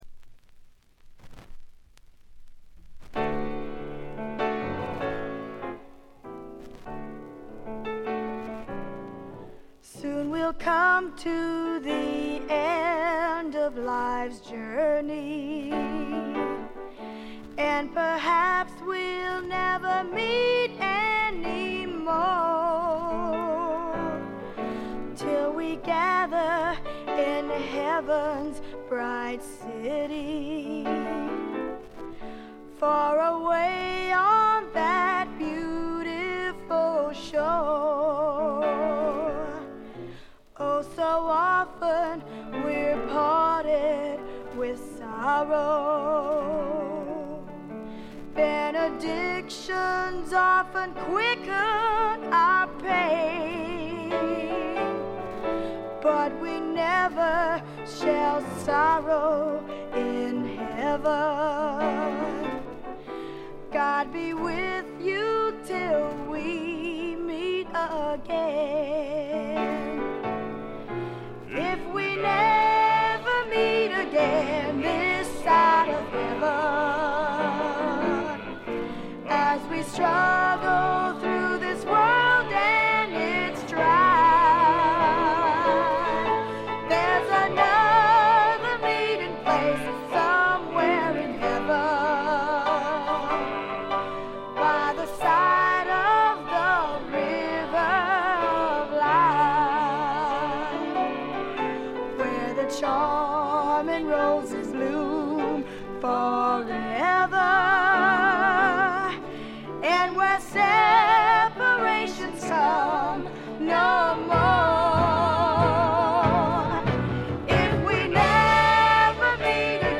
微細なバックグラウンドノイズ程度。
知る人ぞ知るゴスペル・スワンプの名作！
リードシンガーは男２、女２。
試聴曲は現品からの取り込み音源です。